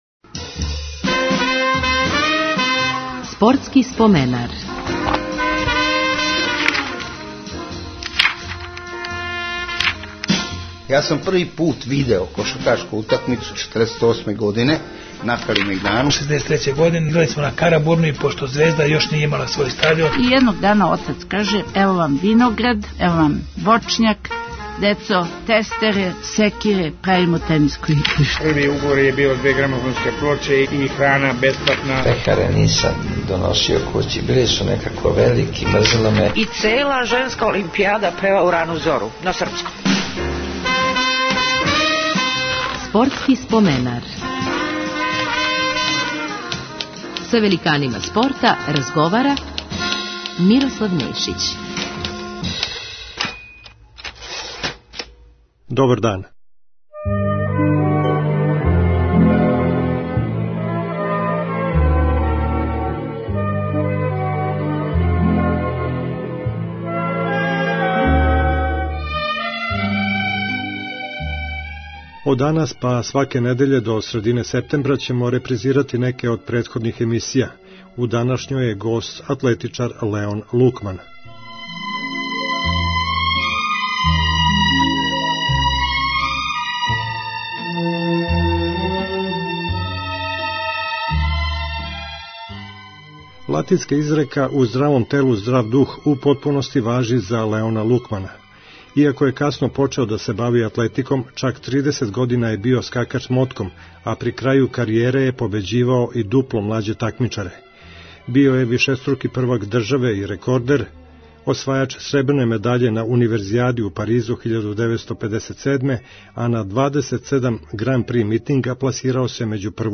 од 16.00 Почев од ове емисије, па све до средине септембра слушаћете репризе емисије Спортски споменар.